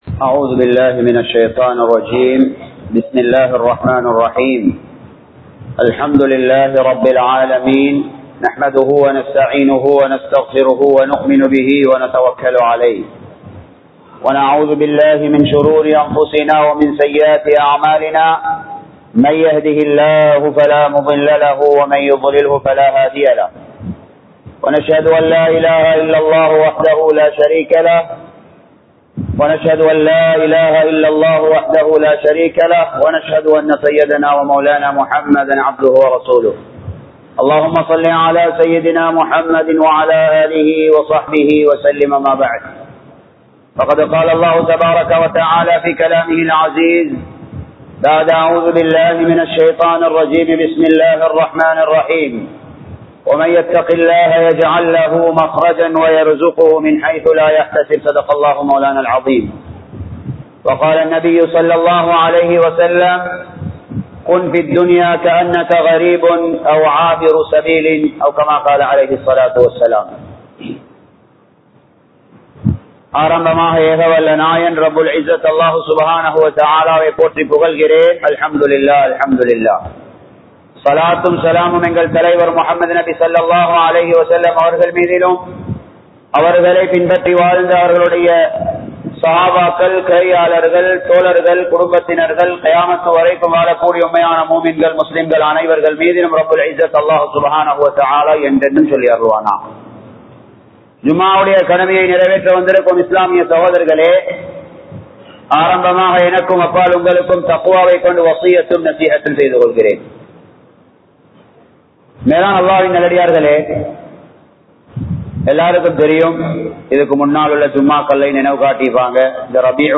பிறரின் குறைகளை தேடாதீர்கள் | Audio Bayans | All Ceylon Muslim Youth Community | Addalaichenai
Colombo 12, Aluthkade, Muhiyadeen Jumua Masjidh 2025-09-12 Tamil Download